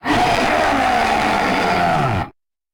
taunt1.ogg